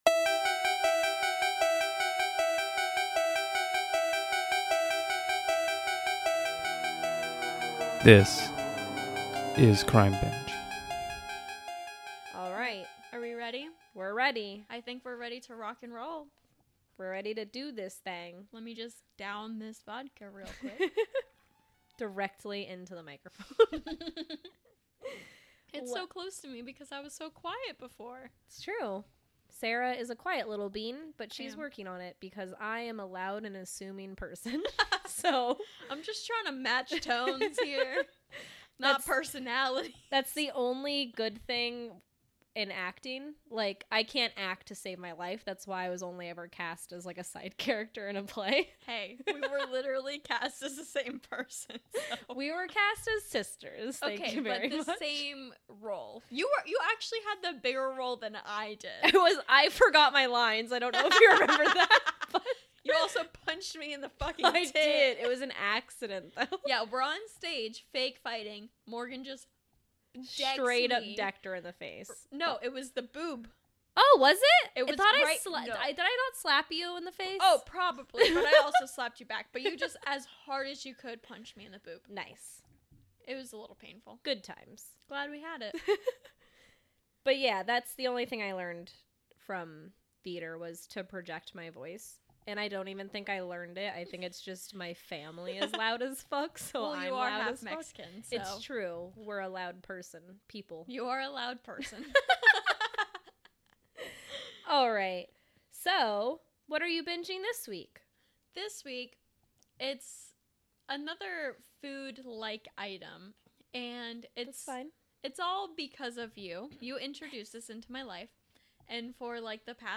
This week on Crime Binge the slap happy girls discuss Charles Ng and Leonard Lake, a crime duo whose story is full of "oh no!" facts.